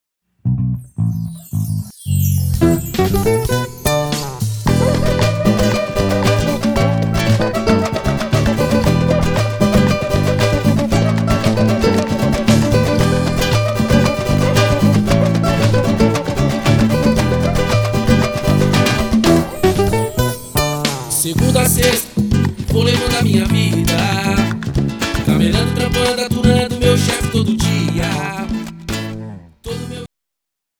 Backing Vocal e rebolo
Contrabaixo elétrico
Bateria